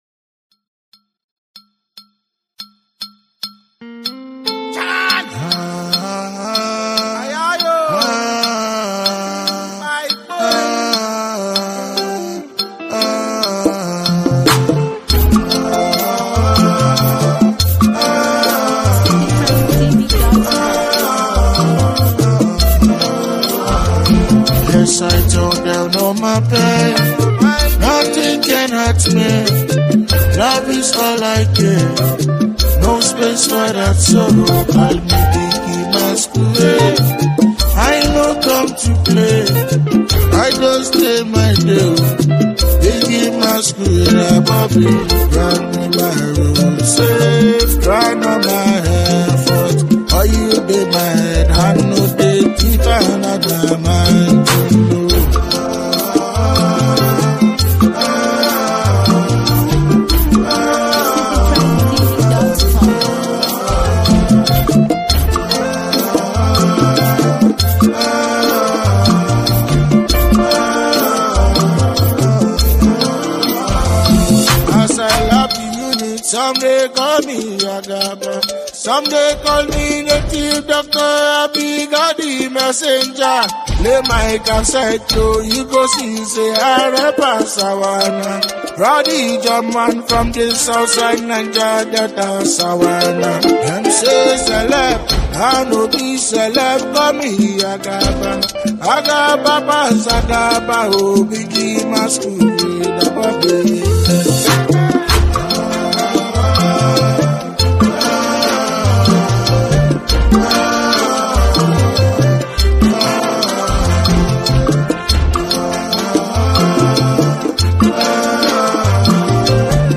Nigerian Afro-fusion